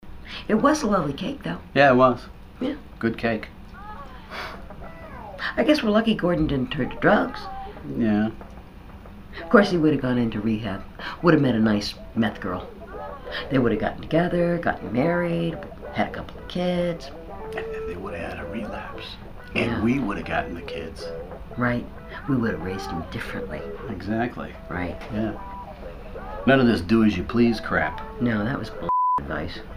As Gordon's parents, John Heard and Beverly D'angelo strike a chord.